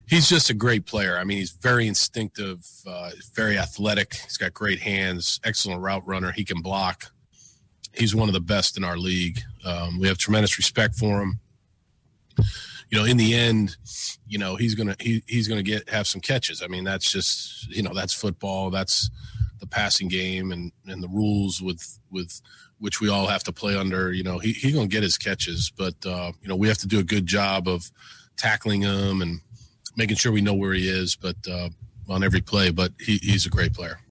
Bill O’ Brien spoke about stopping Travis Kelce: